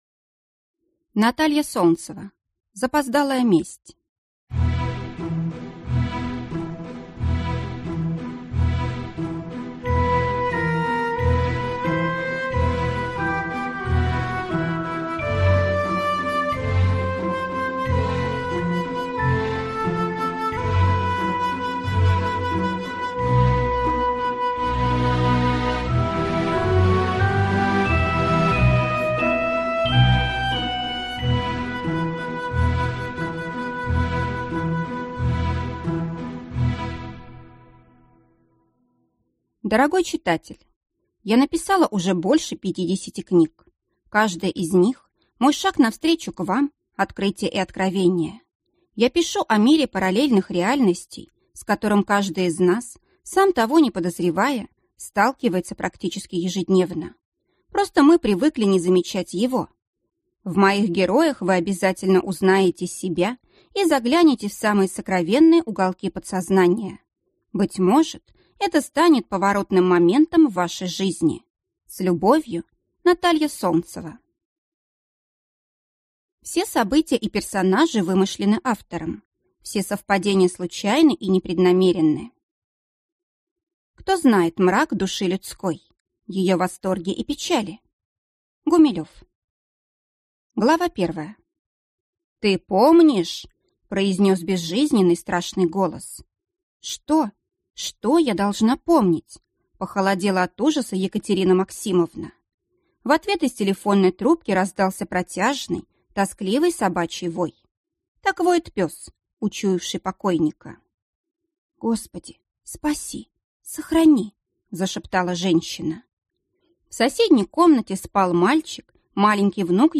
Аудиокнига Запоздалая месть | Библиотека аудиокниг